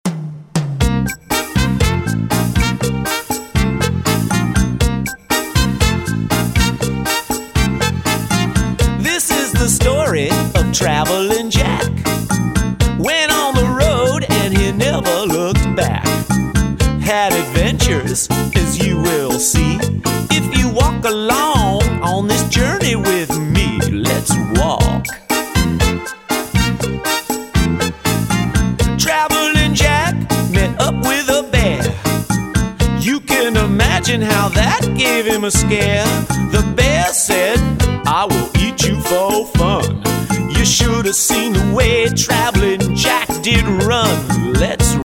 movement songs